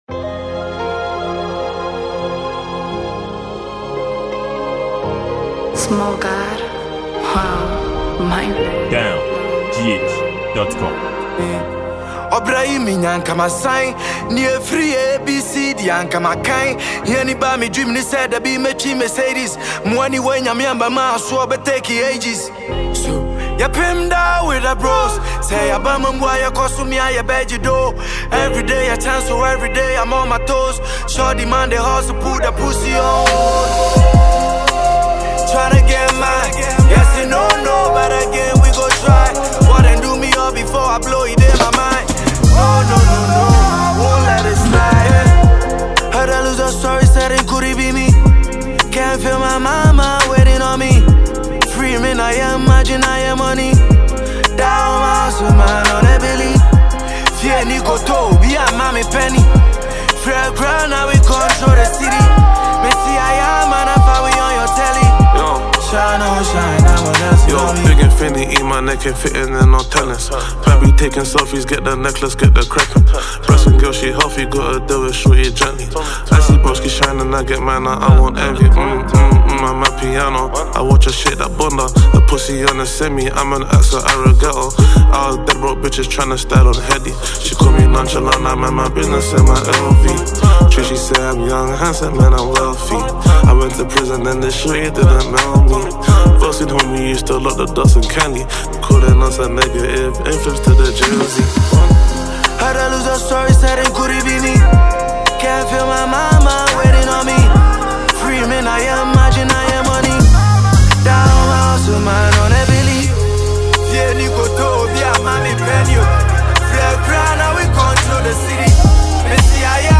Genre: Hiphop